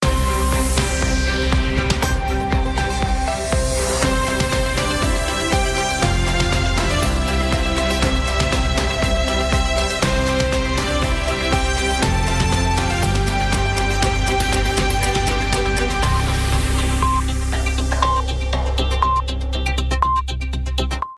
Sintonia de la cadena prèvia als senyals horaris